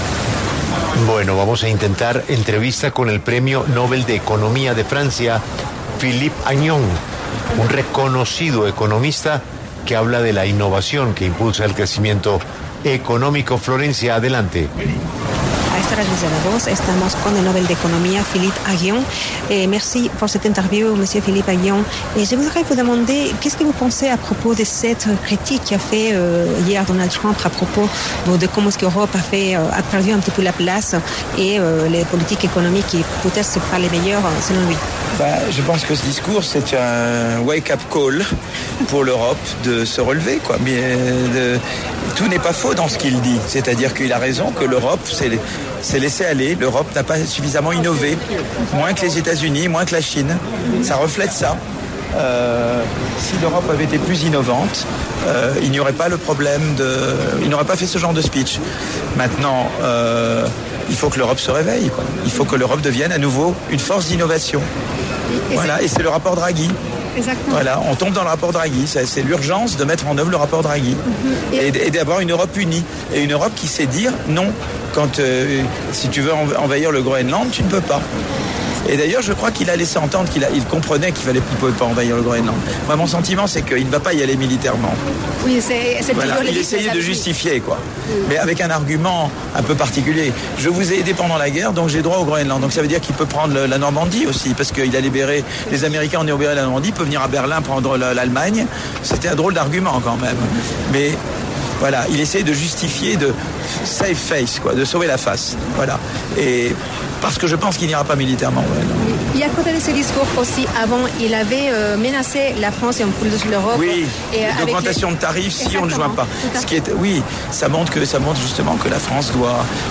En entrevista con 6AM W de Caracol Radio, Aghion abordó las declaraciones de Trump sobre las políticas económicas europeas, sugiriendo que, aunque no todo lo dicho es falso, Europa se ha “dejado llevar” y no ha innovado lo suficiente, quedando rezagada respecto a Estados Unidos y China.